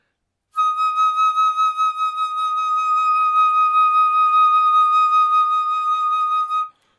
横笛：颤音C4 C7 " 横笛 C6 颤音
描述：这是一个横笛在第六个八度演奏CSharp的乐器样本。这个音符的攻击和保持是Tenuto和Vibrato。使用的调谐频率（音乐会音高）是442，动态意图是中音。这个样本属于一个多样本包 乐队乐器。横笛乐器。AerophoneAerophone。木管乐器音符：C八度。6音乐会音高：442Hz动态：Mezzoforte攻击。攻击：Tenuto持续。颤音麦克风。ZoomH2N话筒设置。XY
标签： 多重采样 放大H2N 颤音 持续音 mezzoforte C-尖锐-6 木管乐器 管乐器 横向槽
声道立体声